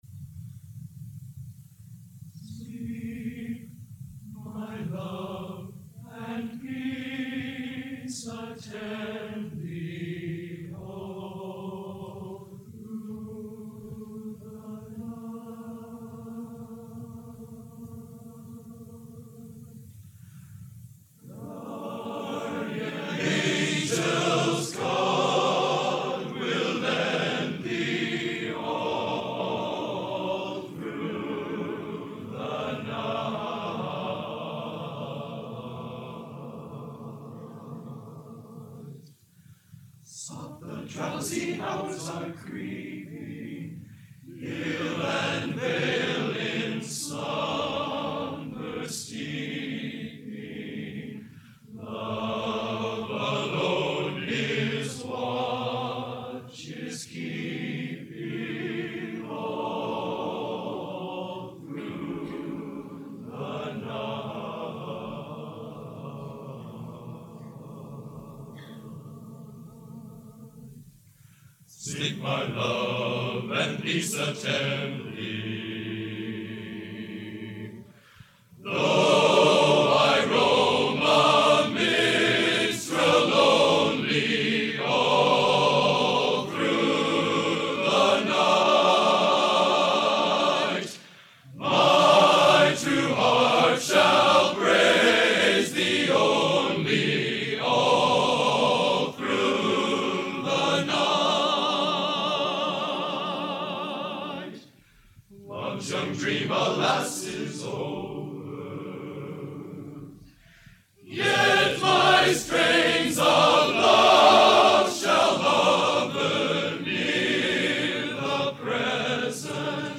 Genre: A Cappella Traditional | Type: End of Season